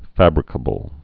(făbrĭ-kə-bəl)